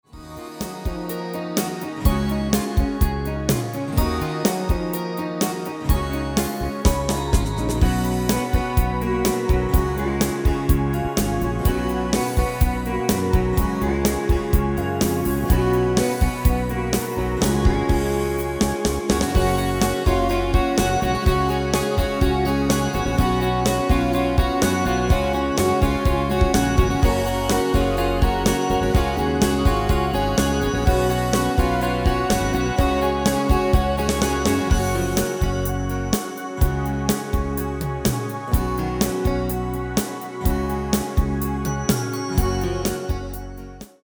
Demo/Koop midifile
Genre: Pop & Rock Internationaal
Toonsoort: Gb
- Vocal harmony tracks
Demo's zijn eigen opnames van onze digitale arrangementen.